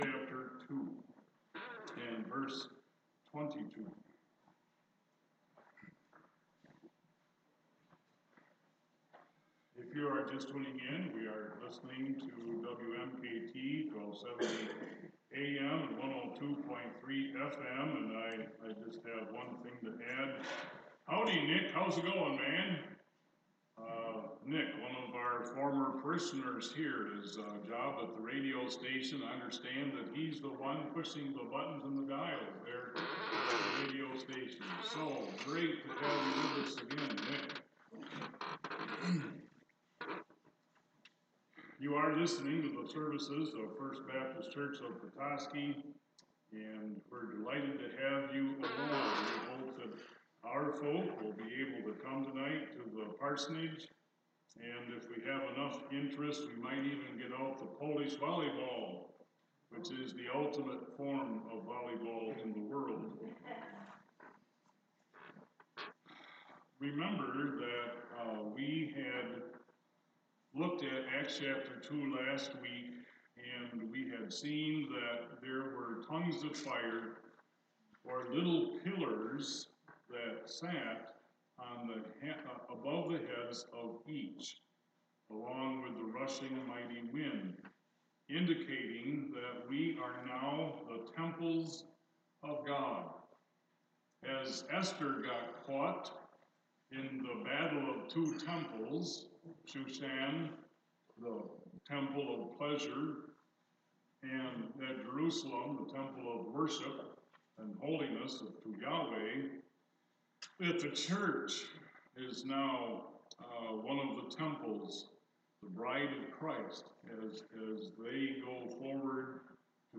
Sunday Morning Message 7-7-2019 | First Baptist Church of Petoskey Sunday Morning Bible Teaching